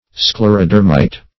Search Result for " sclerodermite" : The Collaborative International Dictionary of English v.0.48: Sclerodermite \Scler`o*der"mite\, n. (Zool.)
sclerodermite.mp3